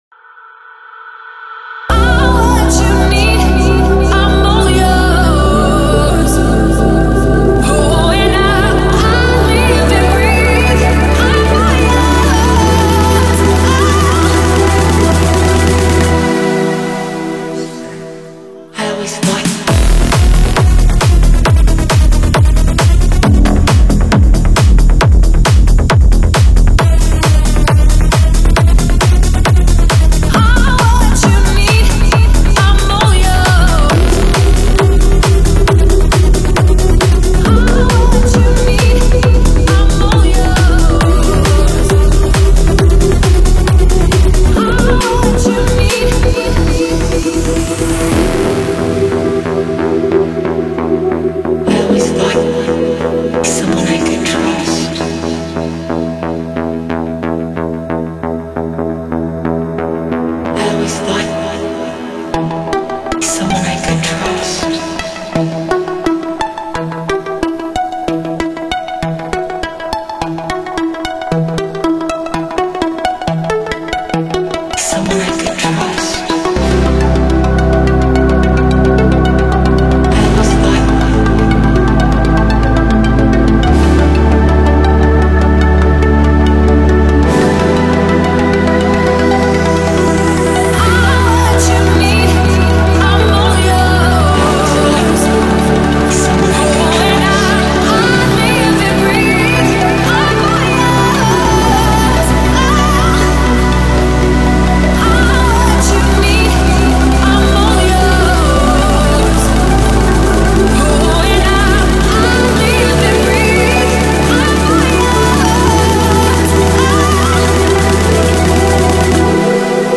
• Жанр: Techno, Dance